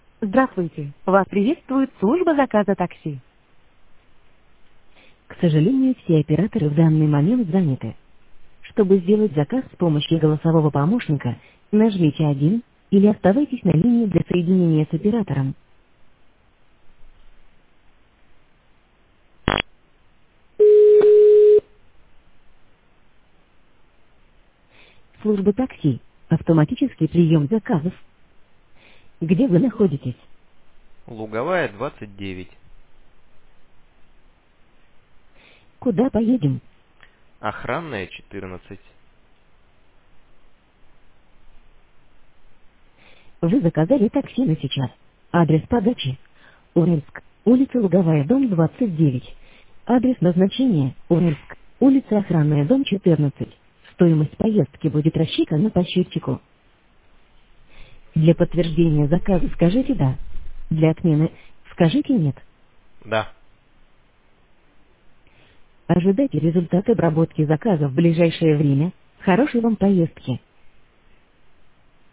Робот общается с клиентом приятным женским голосом и пошагово помогает ему оформить заказ.
Послушать, как робот общается с пассажирами
golosovoy-robot-taxi-master.mp3